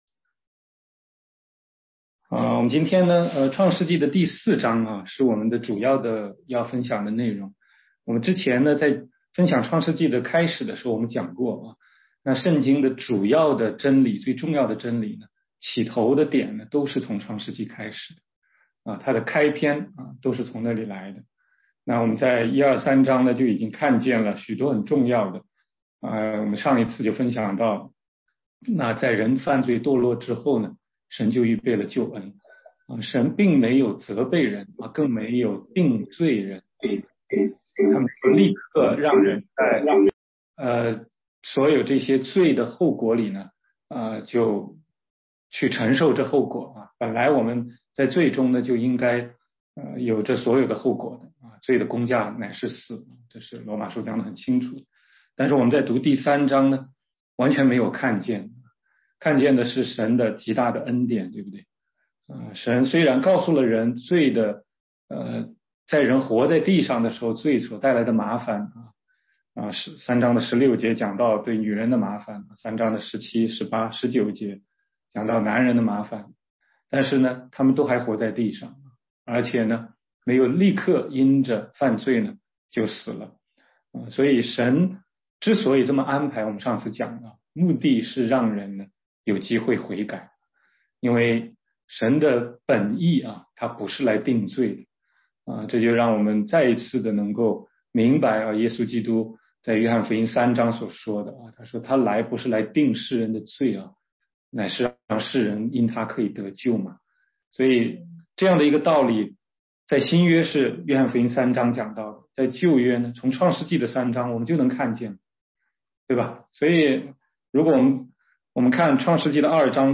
16街讲道录音 - 福音课第四十五讲